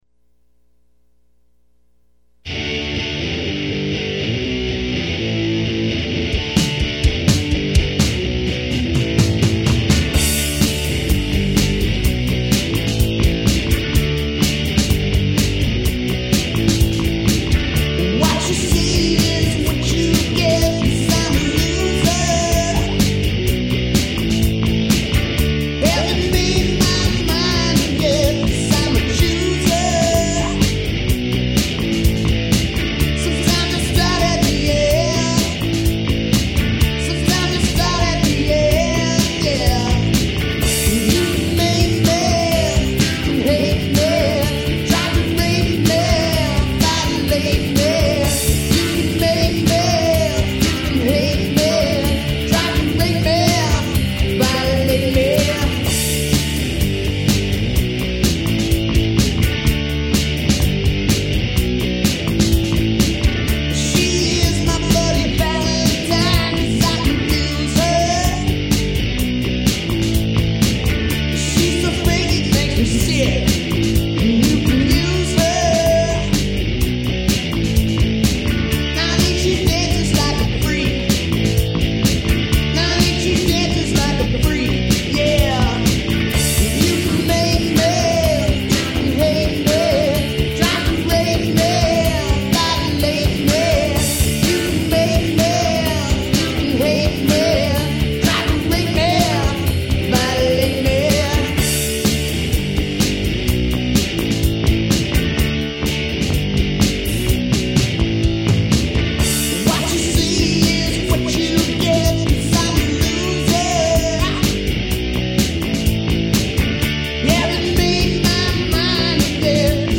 Hard hitting, rythmic mayhem with a hook!